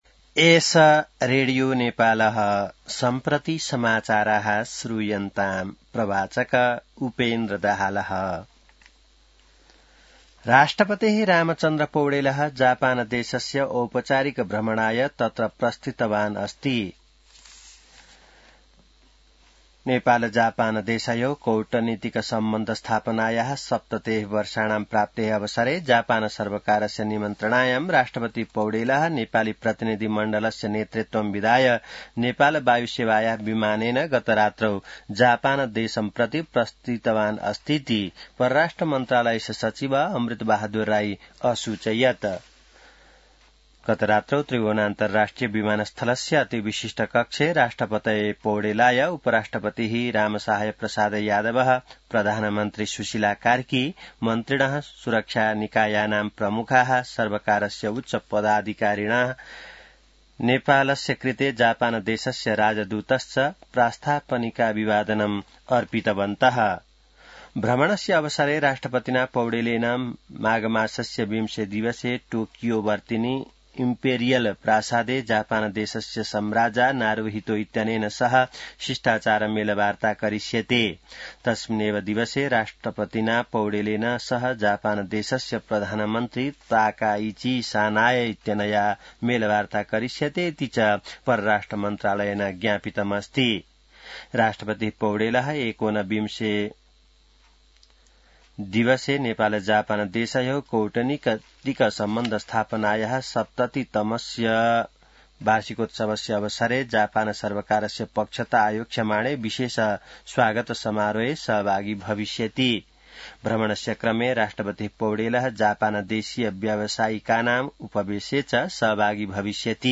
संस्कृत समाचार : १९ माघ , २०८२